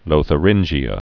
(lōthə-rĭnjē-ə)